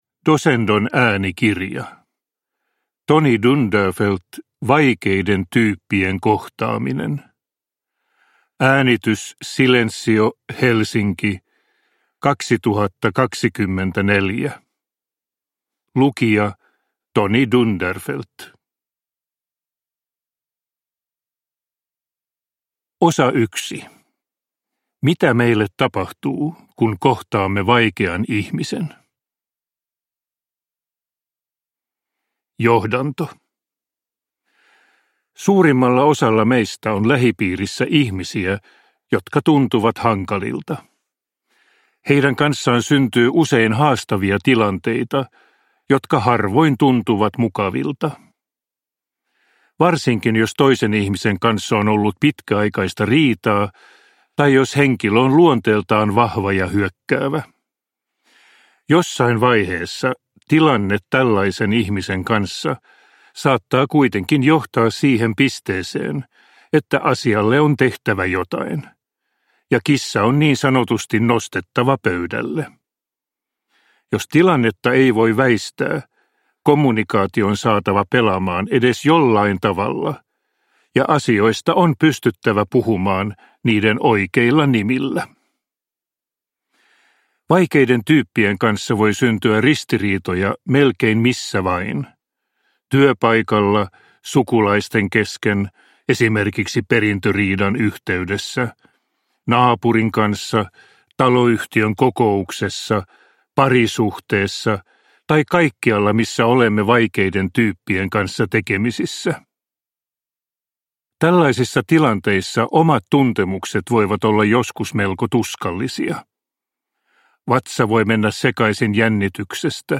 Vaikeiden tyyppien kohtaaminen – Ljudbok